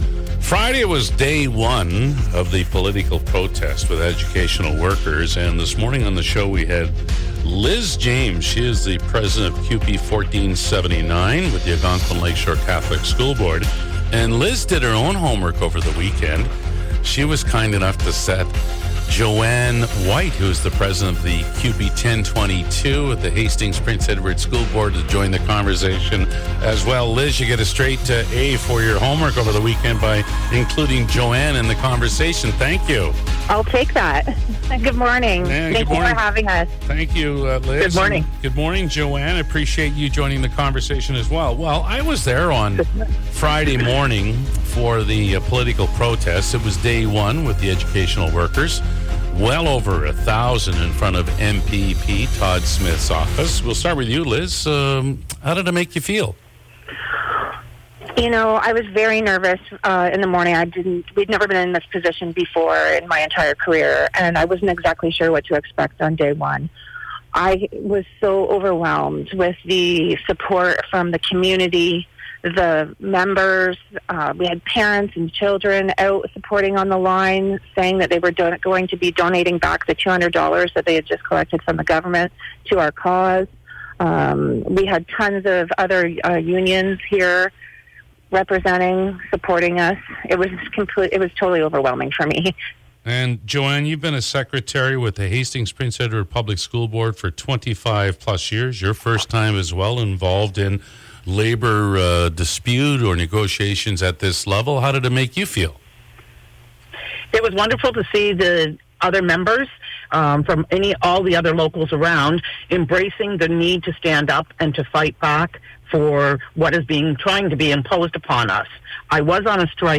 Day 2 of CUPE political protest: In conversation